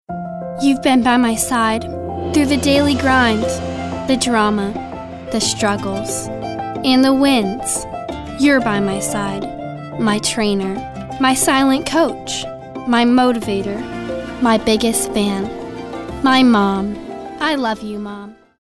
compelling, confident, genuine, girl-next-door, inspirational, kid-next-door, motivational, nostalgic, real, sincere, teenager, thoughtful, warm, young, younger